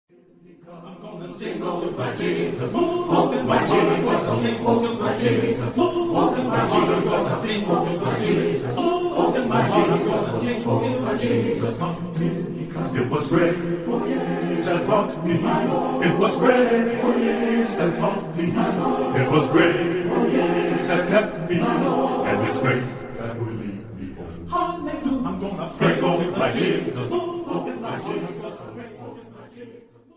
Genre-Style-Forme : Sacré ; Spiritual Afro-Américain
Caractère de la pièce : extatique ; jubilatoire
Type de choeur : SATB + T  (5 voix mixtes )
Tonalité : fa mineur